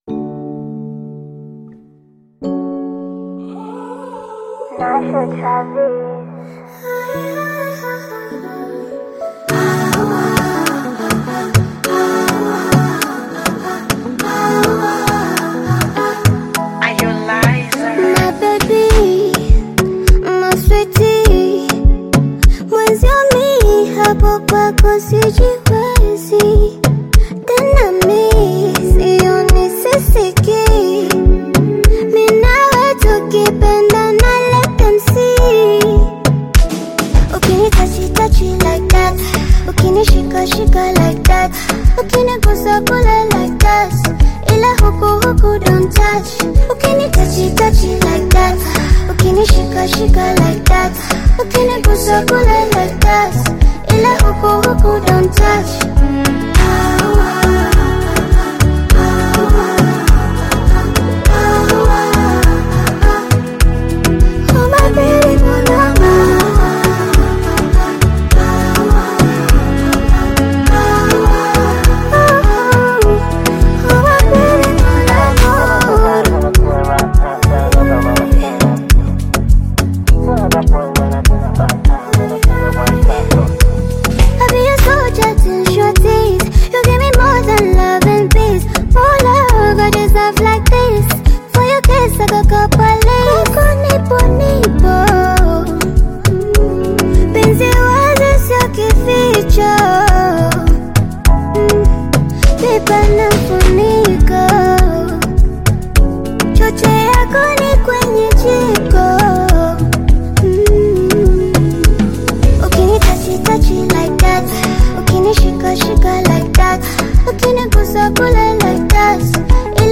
Afro RnB and Pop
combines Afro RnB elegance with Pop vibrancy
Afrobeats